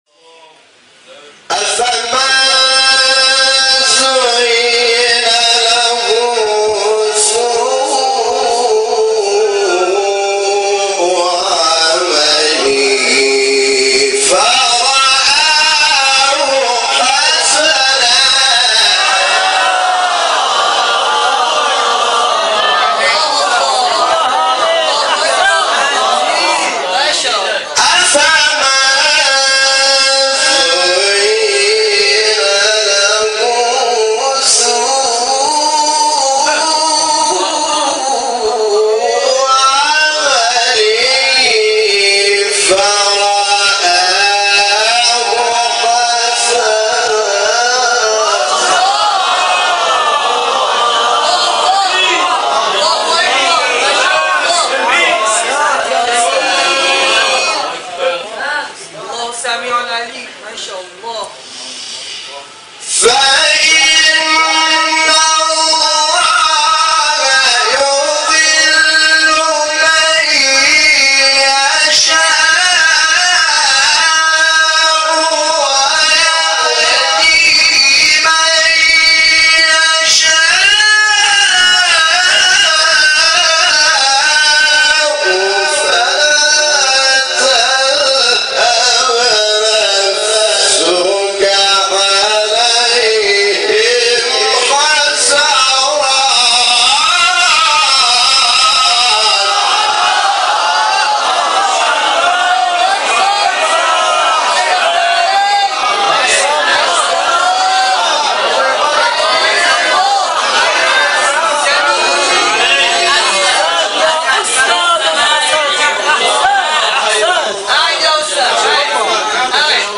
آیه 9-8 فاطر استاد حامد شاکرنژاد مقام بیات | نغمات قرآن | دانلود تلاوت قرآن